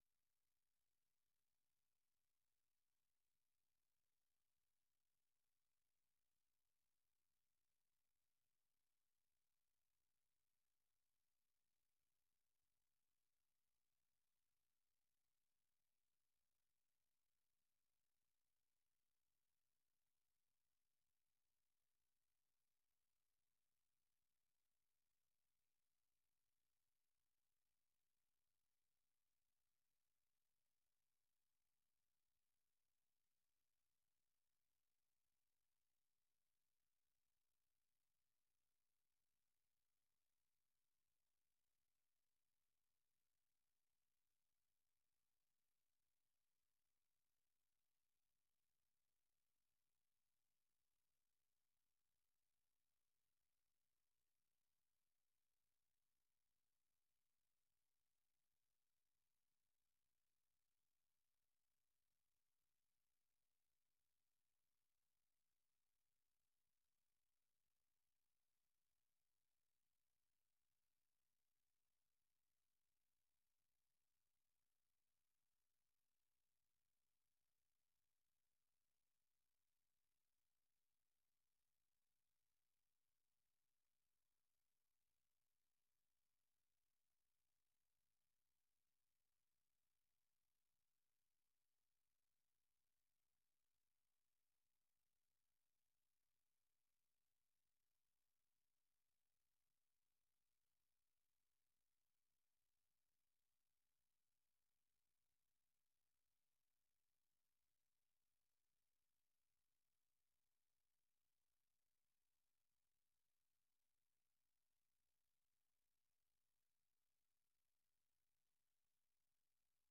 Listen Live - 粵語廣播 - 美國之音
The URL has been copied to your clipboard 分享到臉書 分享到推特網 No media source currently available 0:00 0:30:00 0:00 下載 128 kbps | MP3 64 kbps | MP3 時事經緯 時事經緯 分享 時事經緯 分享到 美國之音《時事經緯》每日以30分鐘的時間報導中港台與世界各地的重要新聞，內容包括十分鐘簡短國際新聞，之後播出從來自世界各地的美國之音記者每日發來的採訪或分析報導，無論發生的大事與你的距離是遠還是近，都可以令你掌握與跟貼每日世界各地發生的大事！